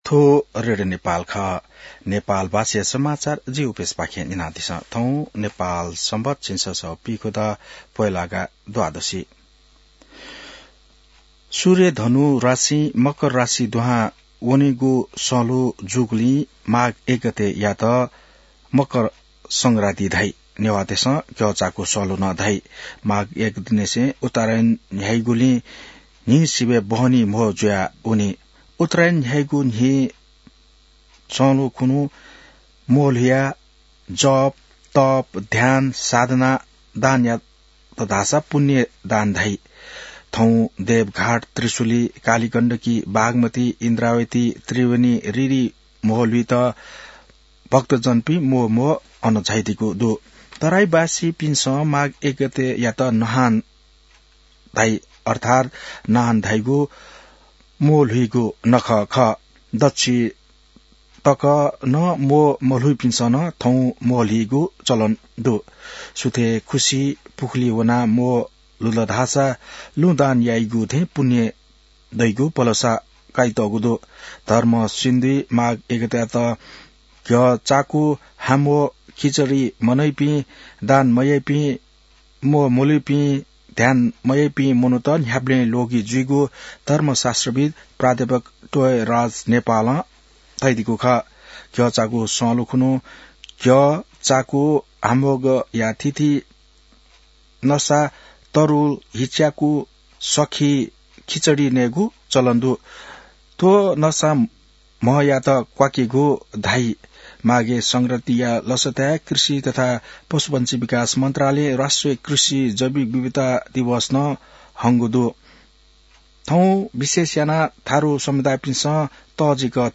An online outlet of Nepal's national radio broadcaster
नेपाल भाषामा समाचार : १ माघ , २०८२